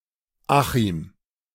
Achim (German pronunciation: [ˈaxɪm]